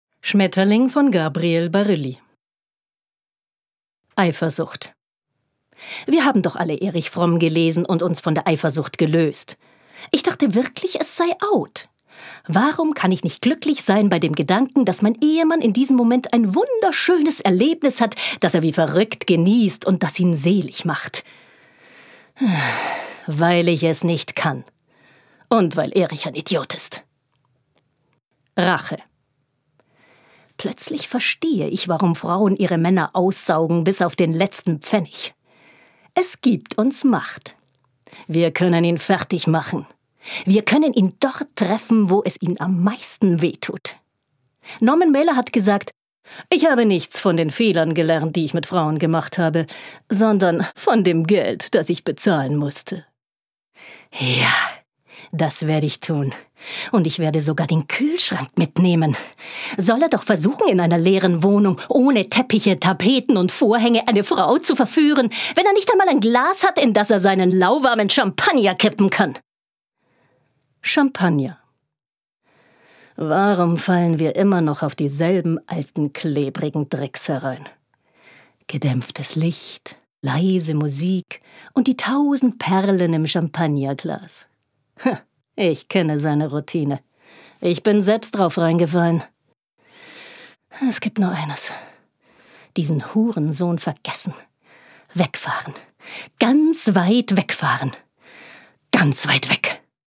Vielseitige Schauspielerin und SĂ€ngerin mit klarer, charaktervoller, direkter Sprache - Mezzosopran.
Sprechprobe: Sonstiges (Muttersprache):